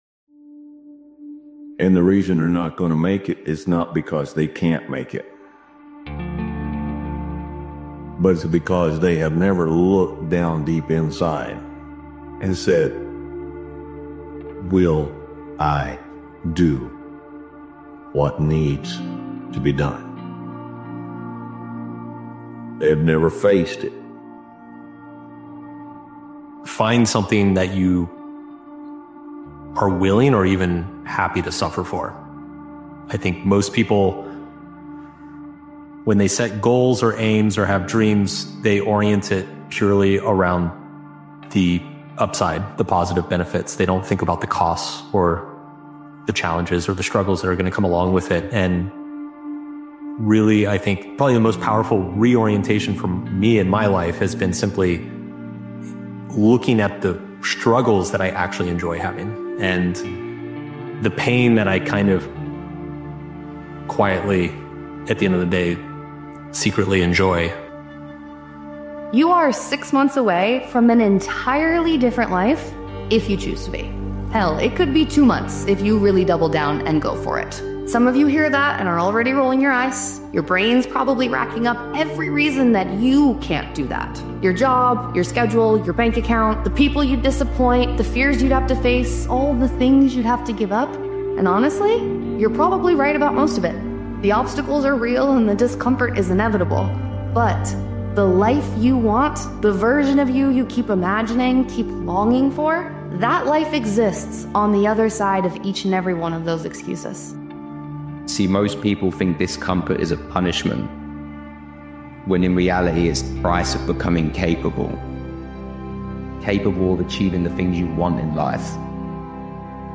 Powerful Motivational Speech is a blunt and self-reflective motivational speech created and edited by Daily Motivations.